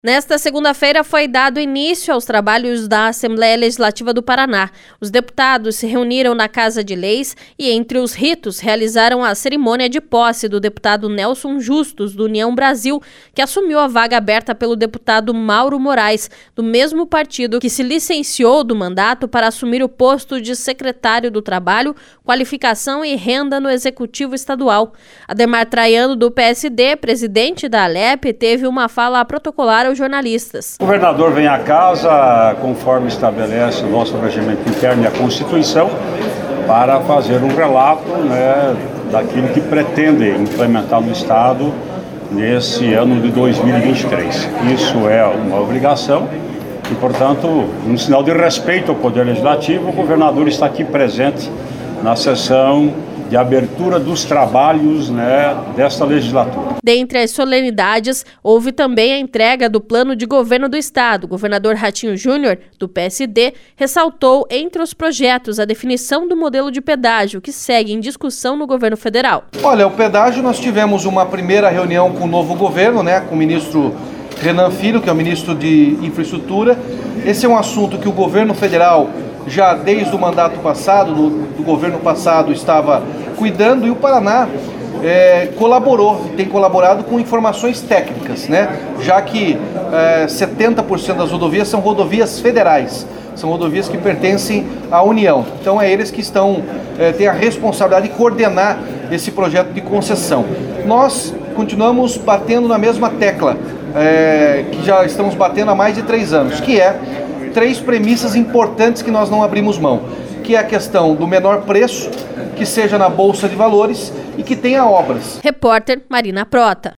Ademar Traiano (PSD), presidente da Alep, teve uma fala protocolar aos jornalistas.